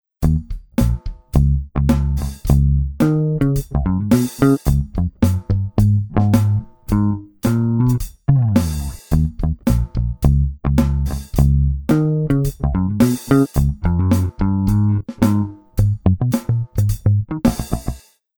the first drum’n’bass sketch of the song
NonVolete-drumbass.mp3
Drums: loops from CM Magazine re-mapped into Melodyne.